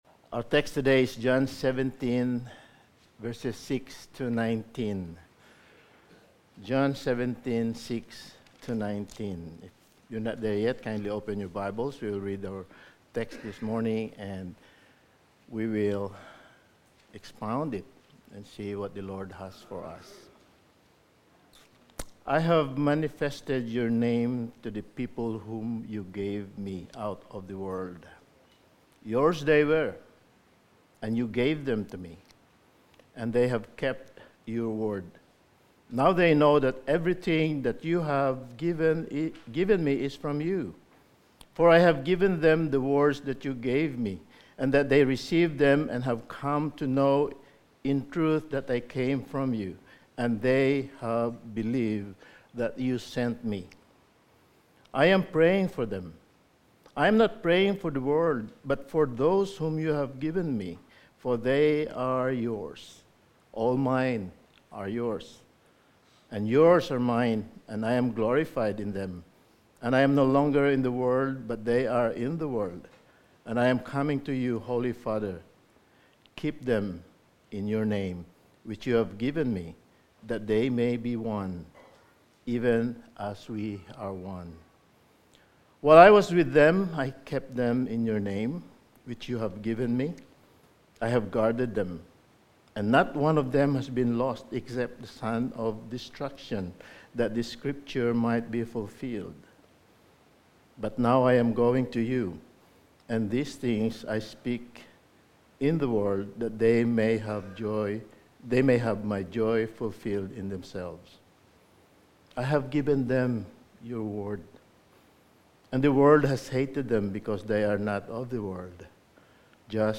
Passage: John 17:6-19 Service Type: Sunday Morning